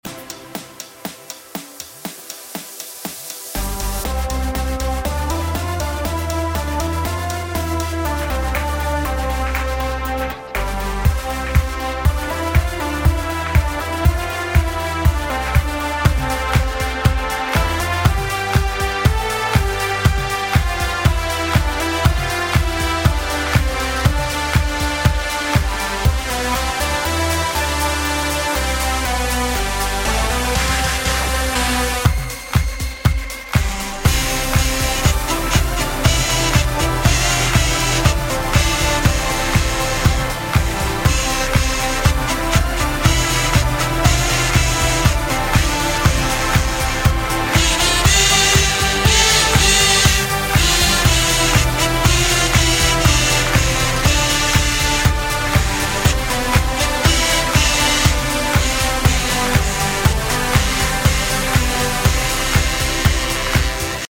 en de fijne dance versie....